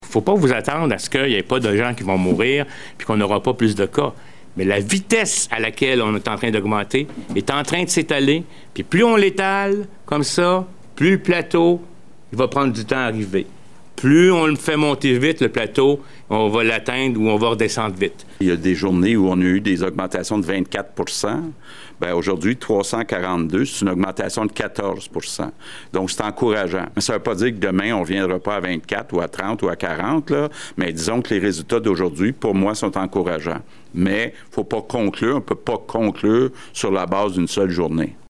Dans sa mise à jour quotidienne, le premier ministre François Legault s’est réjoui de voir que l’augmentation du nombre de cas hebdomadaires semble se stabiliser.
Même s’ils semblent encouragés par les chiffres du jour, autant le directeur national de la santé publique, Horacio Arruda que le  premier ministre Legault,  ont préféré n’exclure aucun scénario :